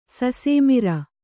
♪ sasēmira